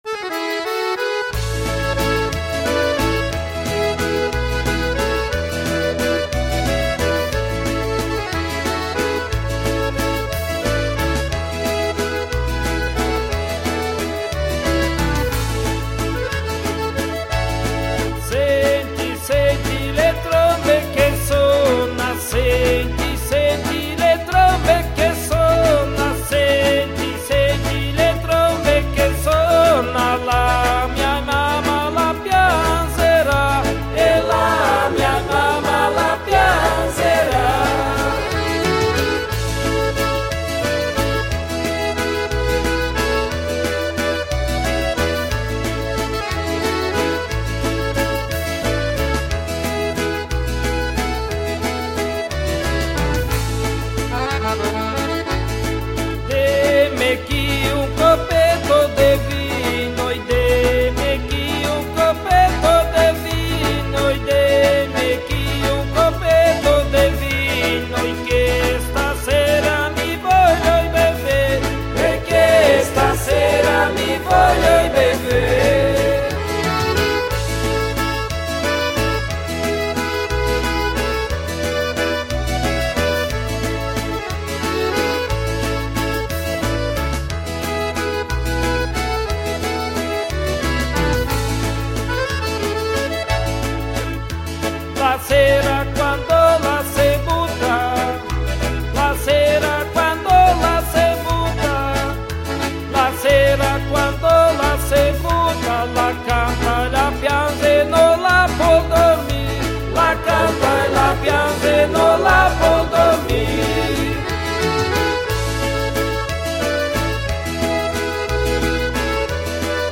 EstiloSertanejo
Composição: Folclore Italiano.